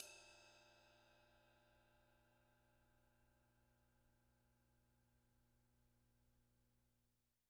R_B Ride 02 - Close.wav